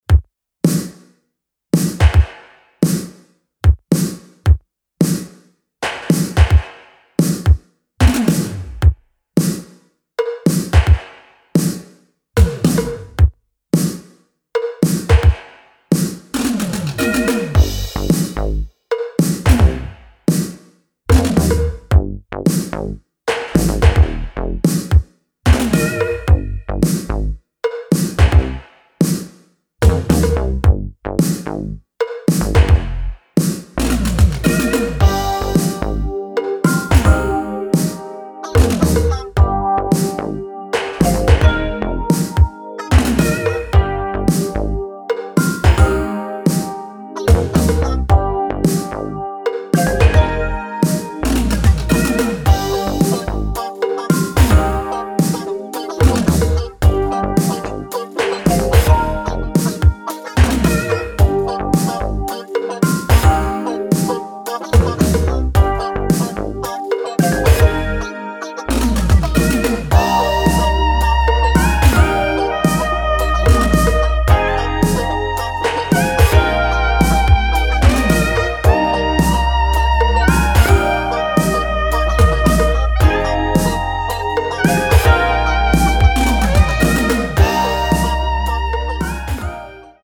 House Funk Ambient